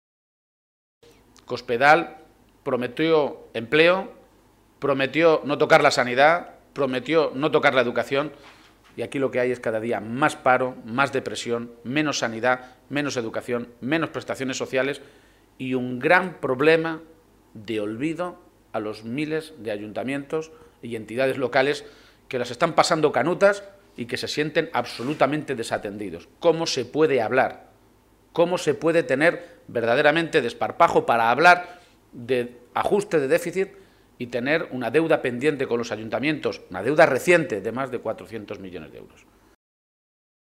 El secretario general del PSOE de Castilla-La Mancha, Emiliano García-Page, ha comparecido hoy ante los medios de comunicación minutos antes de la celebración del primer Comité Regional después de Décimo Congreso que le eligió con un 95 por ciento de los votos nuevo máximo dirigente de los socialistas de Castilla-La Mancha.